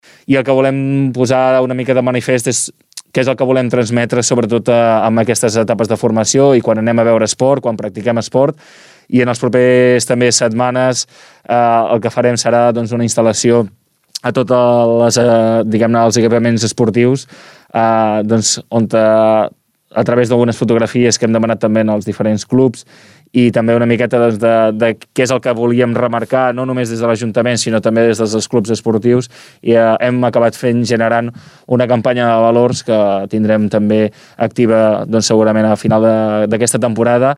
Dins l’entrevista amb els polítics de la setmana passada, el regidor d’esports Pau Megías en va donar més detalls.